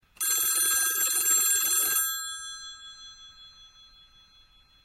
kategori : Sound Effects tag : ring classic phone